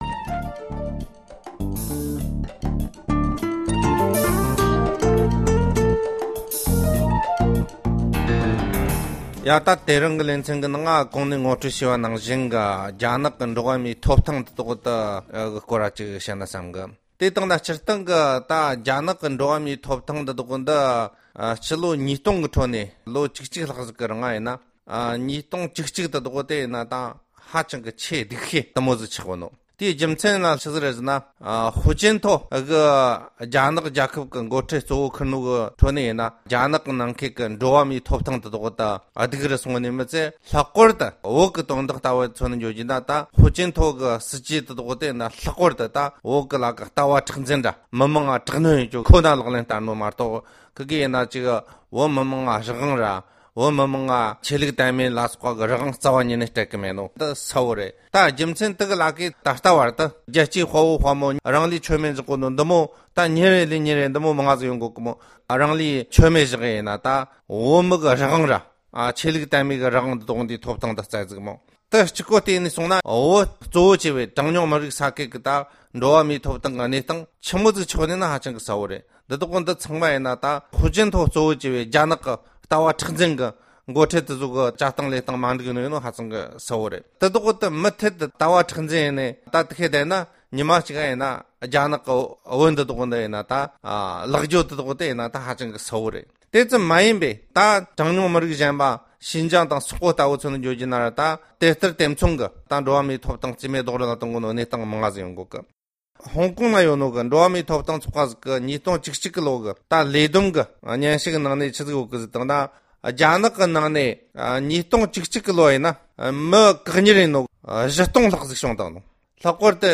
༢༠༡༡ལོ་དེ་ནི་རྒྱ་ནག་གི་འགྲོ་བ་མིའི་ཐོབ་ཐང་གི་གནས་སྟངས་སྡུག་ཤོས་དེར་གྱུར་ཡོད་སྐོར་གྱི་དཔྱད་བརྗོད།